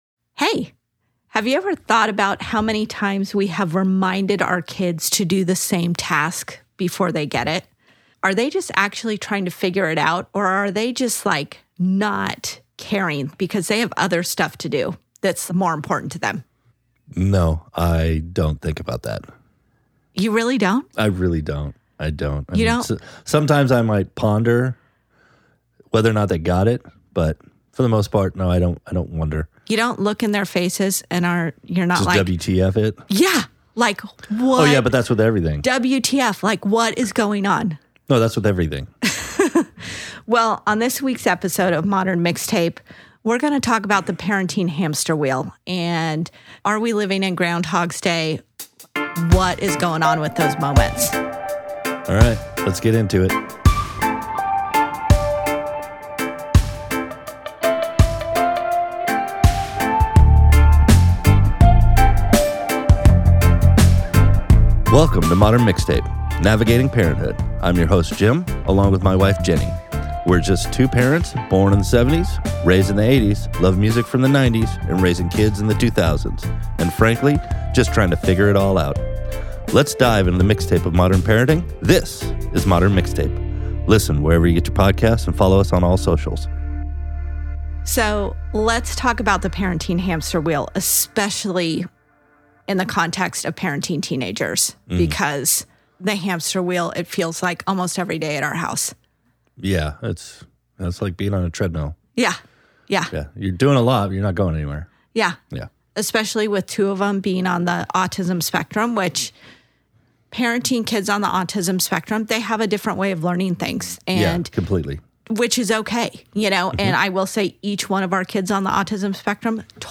You can hear the theme music in the background...it sounds like the circus is coming to town, you can't turn it off, you can't get off.